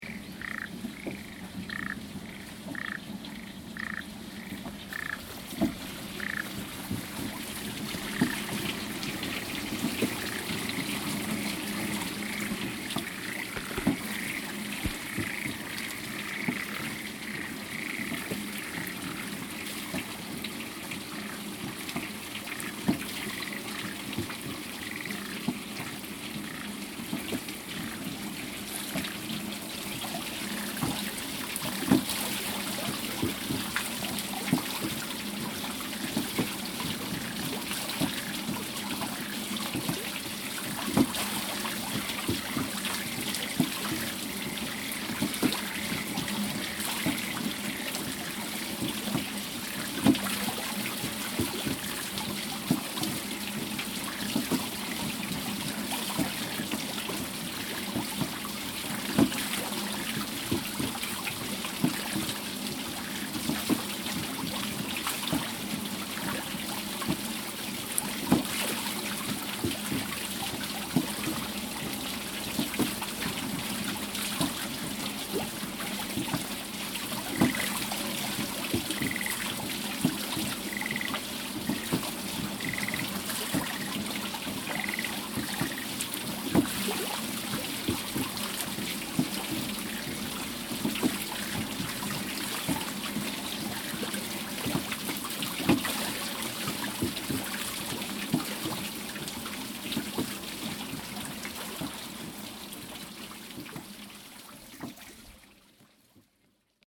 高原の棚田
実は4月に水車の音を録った時、ハグルマが半周ぐらいまわっては戻り、まわっては戻りの運動を繰り返していて、ぐるっと回転していなかったのだ。
シーズン到来で気合いの入った水車の音と、やる気なさげな蛙の声。
kaerusuisya.mp3